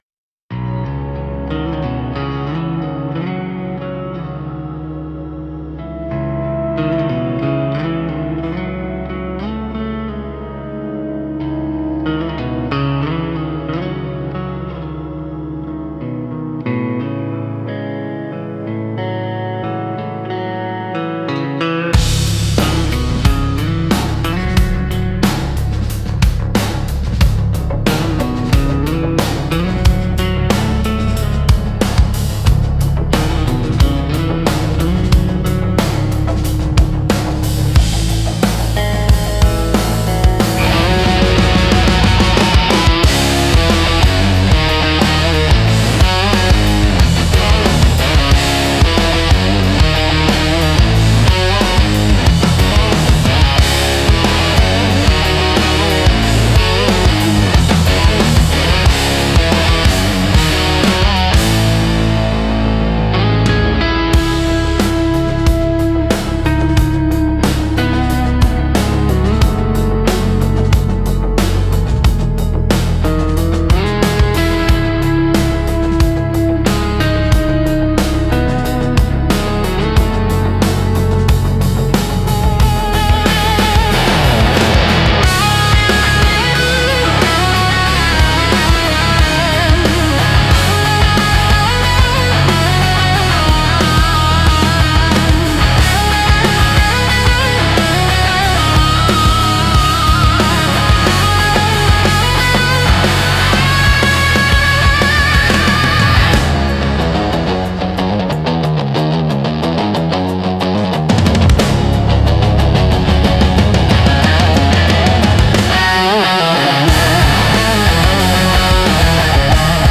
Instrumental - The Grain in the G Chord 5.41